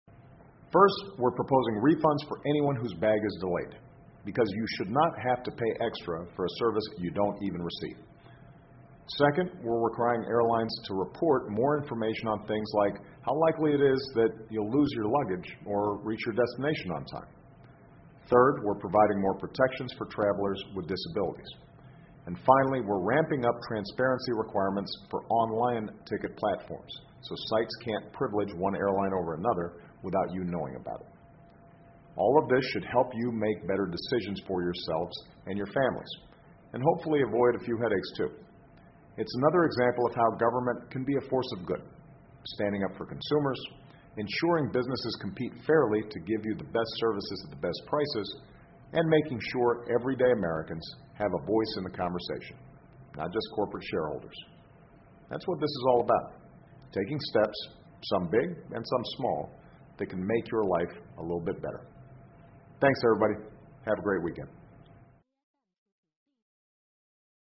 奥巴马每周电视讲话：总统呼吁采取措施激励航空工业竞争（02） 听力文件下载—在线英语听力室